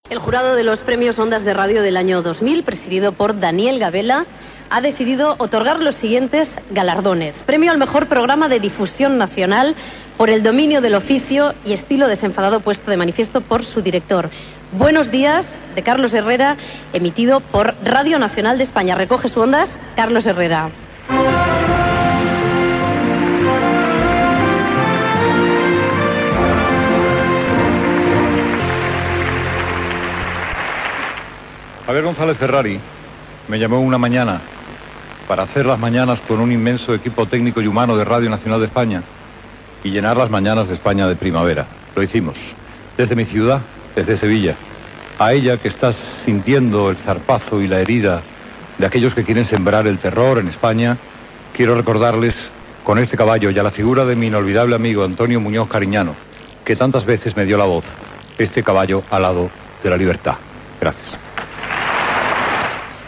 Lliurament del Premi Ondas 2000 a Carlos Herrera, pel programa de RNE "Buenos días". Paraules d'agraïment de Carlos Herrera
Presentador/a